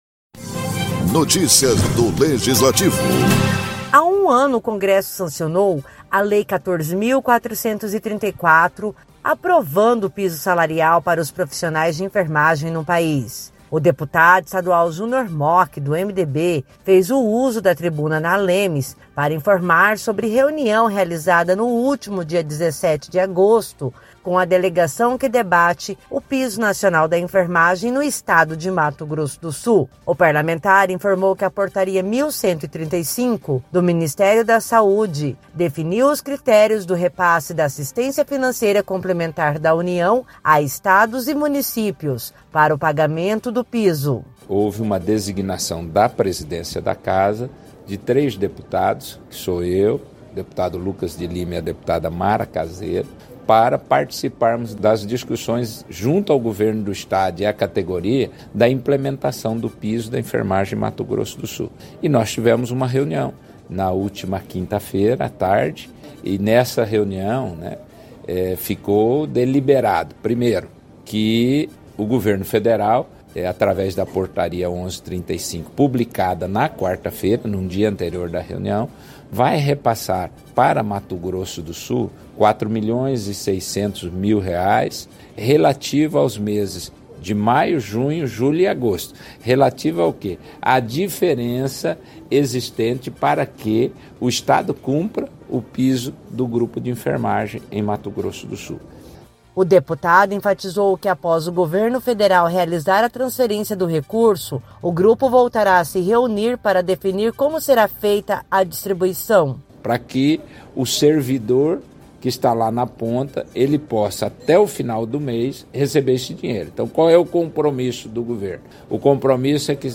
O deputado estadual Junior Mochi, do MDB, fez uso da tribuna na ALEMS  para informar sobre uma reunião realizada com a delegação que debate o Piso Nacional da Enfermagem. A Portaria 1.135, do Ministério da Saúde, definiu os critérios do repasse da assistência financeira complementar da União a Estados e municípios para o pagamento do piso.